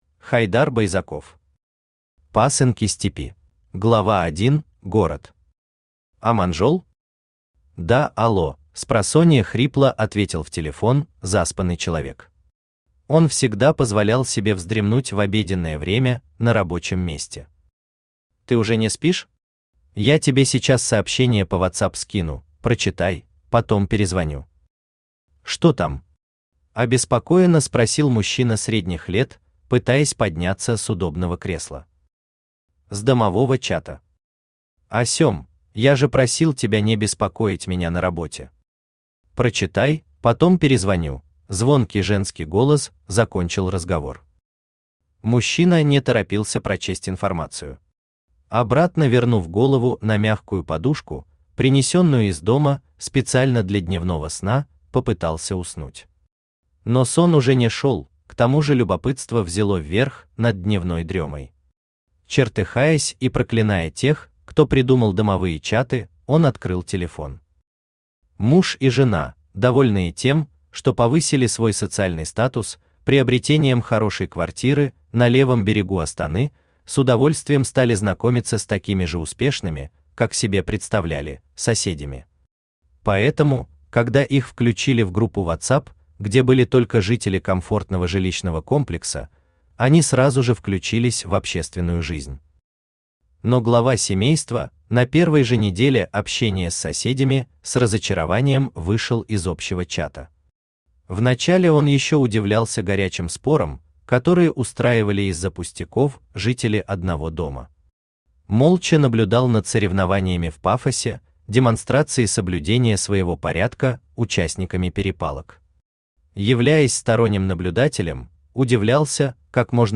Аудиокнига Пасынки Степи | Библиотека аудиокниг
Aудиокнига Пасынки Степи Автор Хайдар Маратович Байзаков Читает аудиокнигу Авточтец ЛитРес.